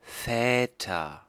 Ääntäminen
Ääntäminen Tuntematon aksentti: IPA: /ˈfɛːtɐ/ Haettu sana löytyi näillä lähdekielillä: saksa Käännöksiä ei löytynyt valitulle kohdekielelle. Väter on sanan Vater monikko.